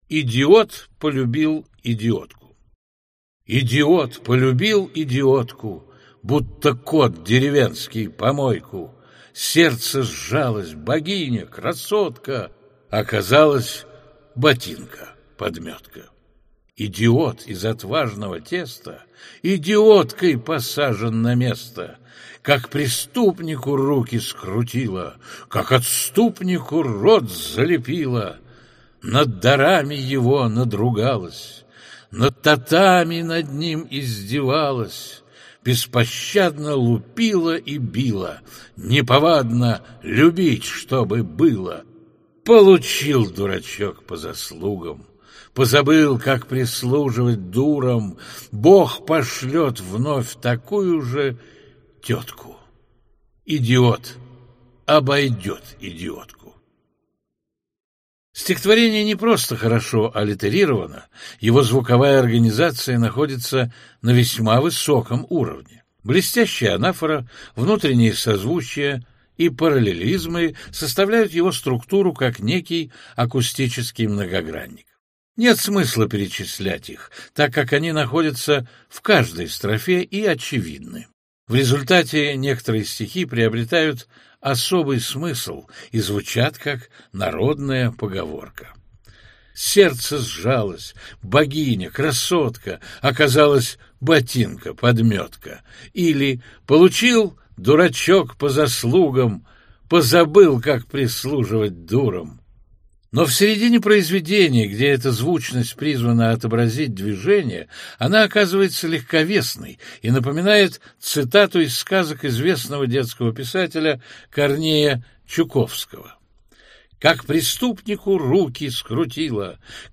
Аудиокнига Литературоведческий анализ стихотворений – 2 | Библиотека аудиокниг